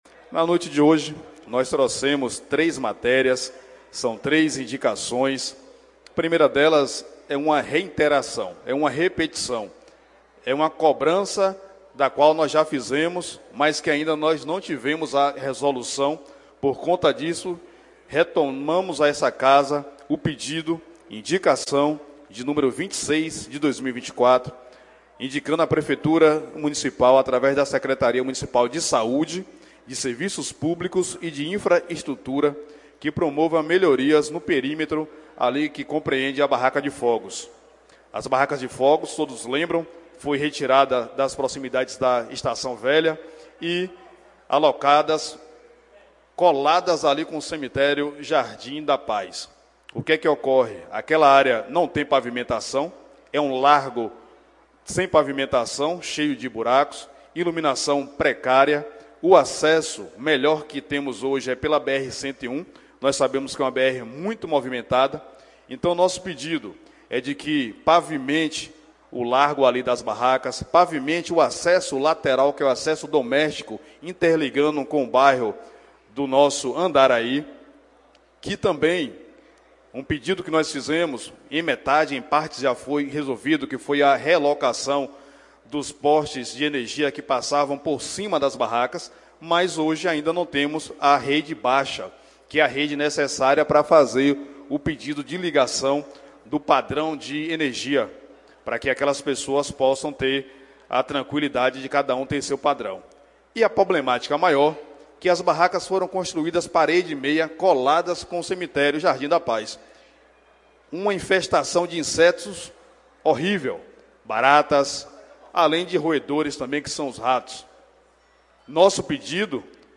Sonora das indicações do vereador Thiago Chagas na 20ª Sessão Ordinária da Câmara de Vereadores.
Confira na íntegra a fala do vereador!